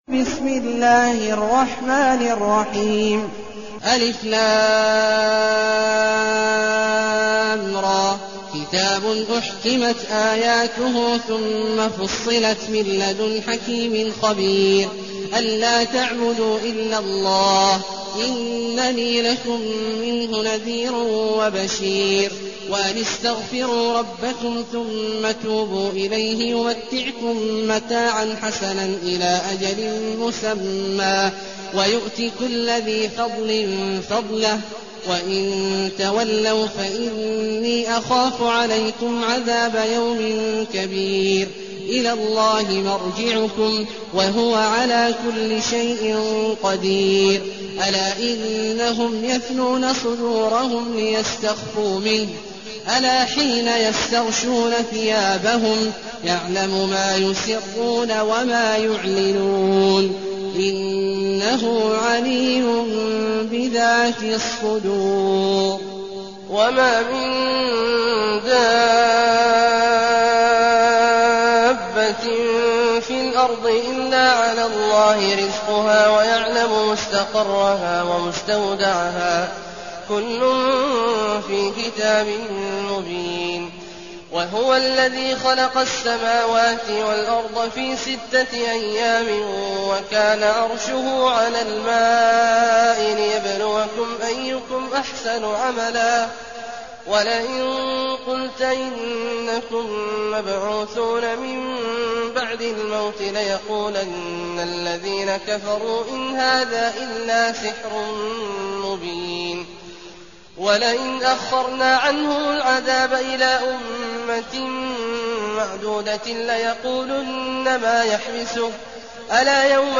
المكان: المسجد النبوي الشيخ: فضيلة الشيخ عبدالله الجهني فضيلة الشيخ عبدالله الجهني هود